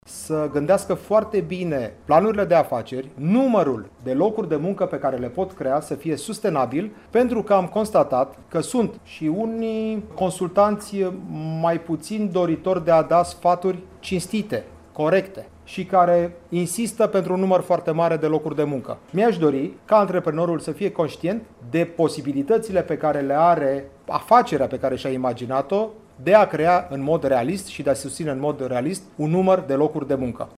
Ministrul pentru Mediul de Afaceri, Comerţ şi Antreprenoriat, Ştefan Radu Oprea, recomandă antreprenorilor să fie realişti în privinţa planului de afaceri şi mai alers a numărului de locuri de muncă propuse:
Radu-Oprea.mp3